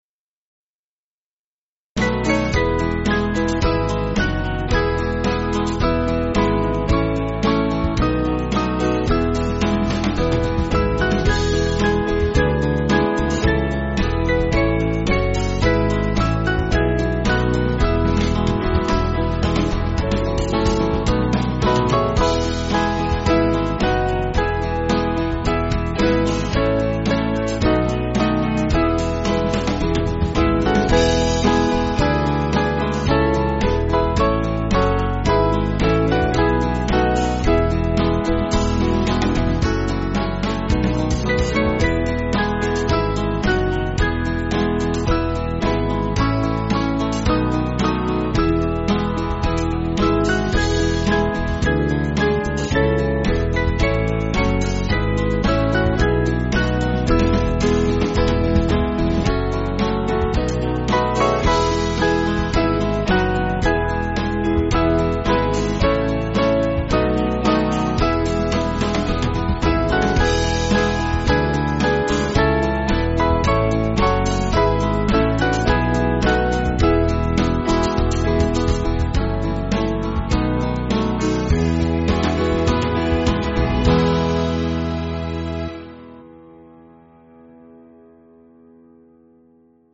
German hymn
Small Band